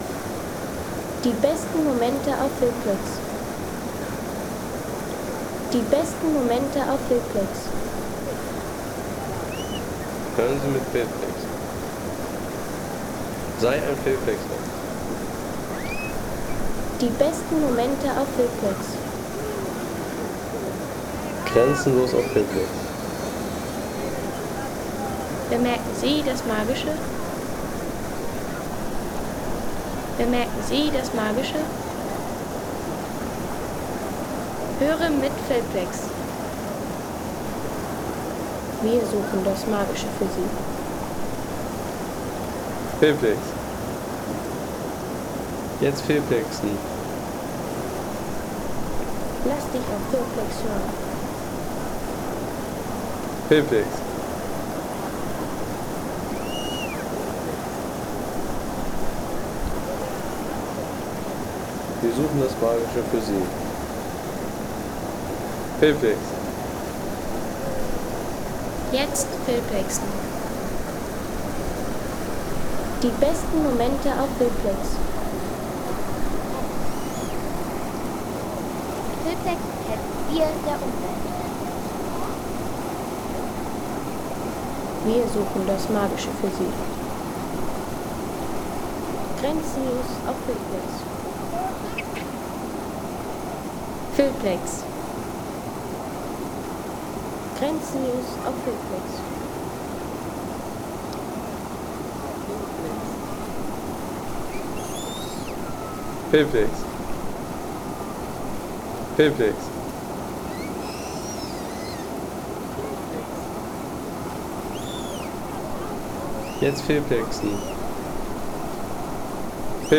Fluss Verzasca
Baden im Fluss Verzasca.